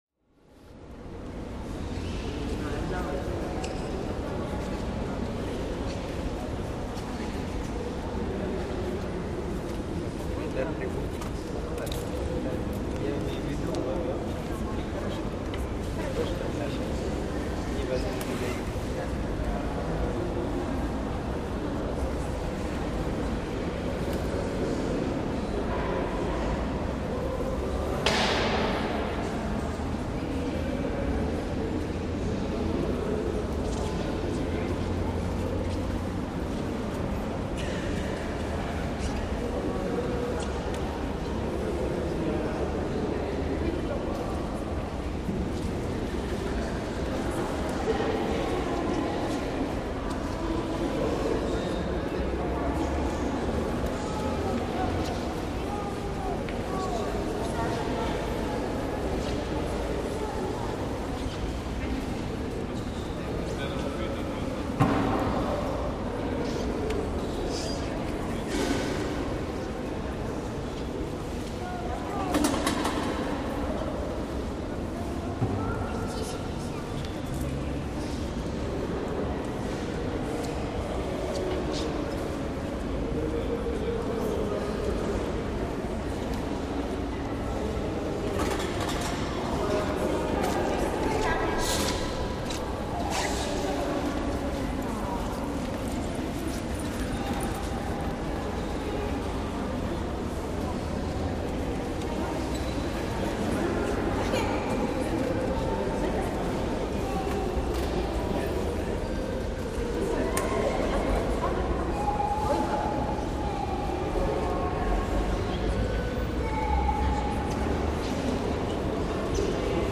Anteroom Large, Ambience